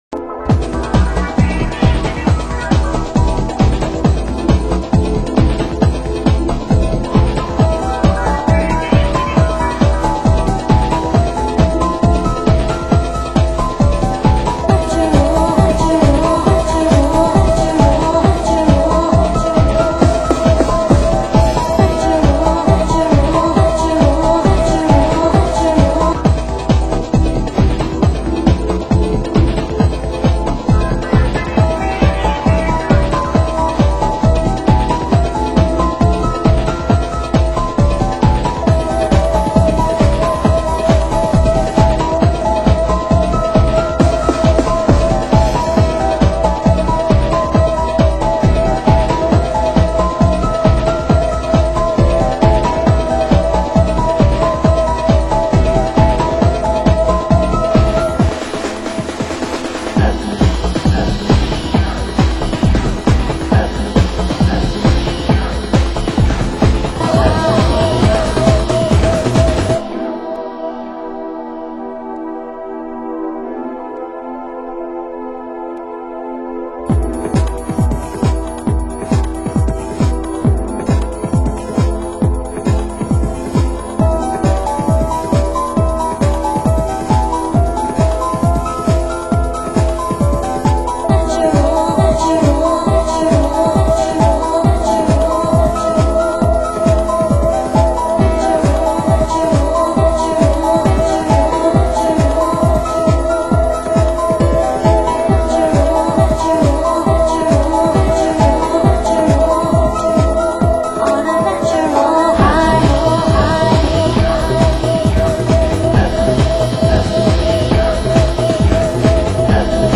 Genre UK House